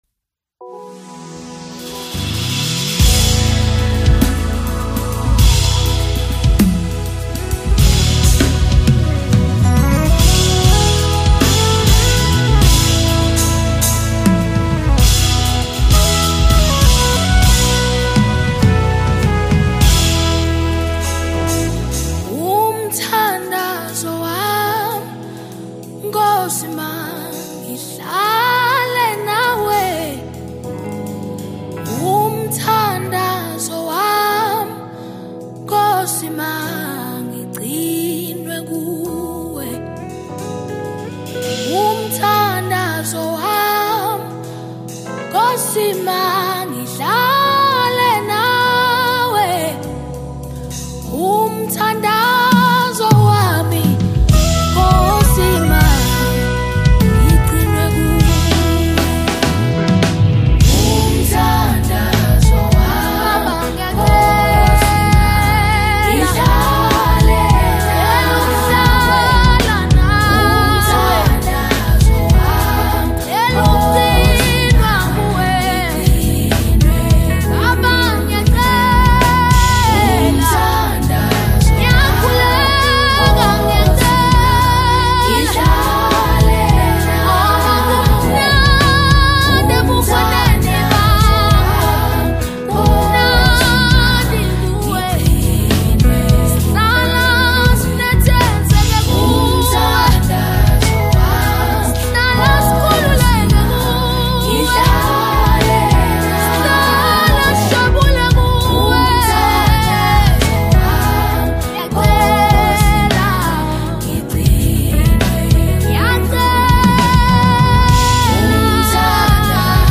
📅 Category: South African Deep Worship Song